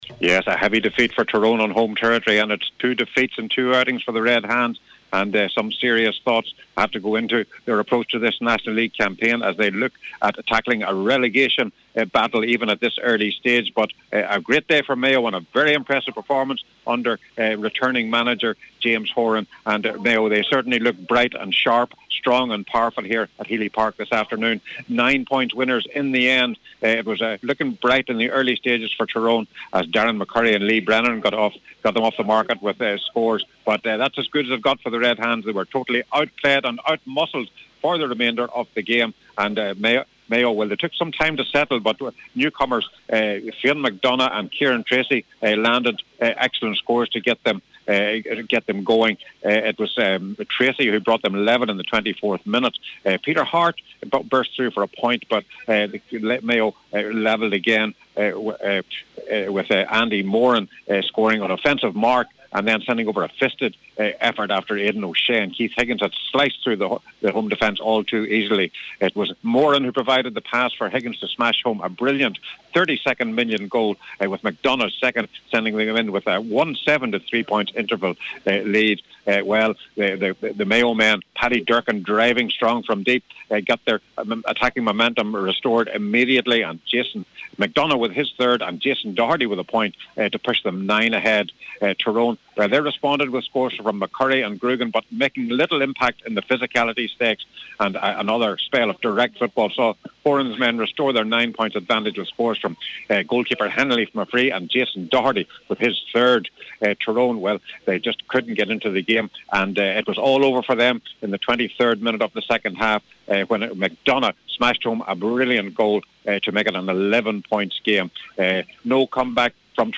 Tyrone manager Mickey Harte spoke after the loss today…